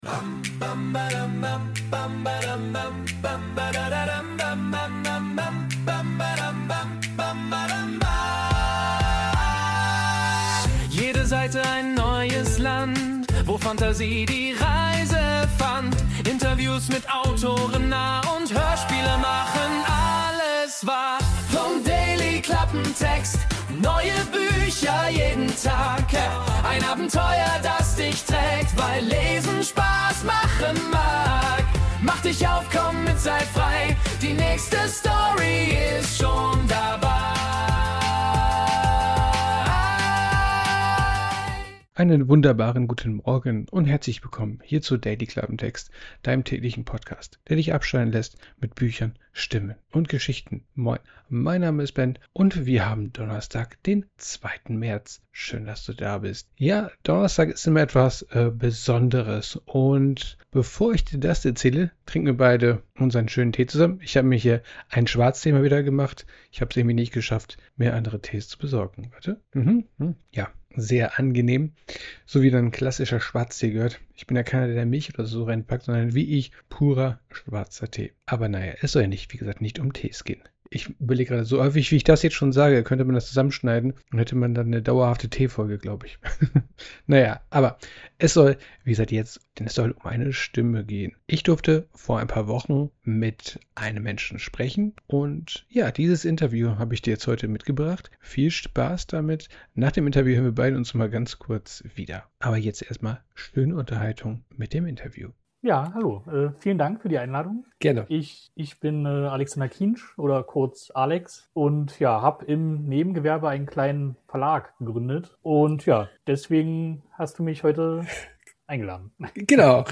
Richtig, ein Interview mit einem Verlag und einem Autor.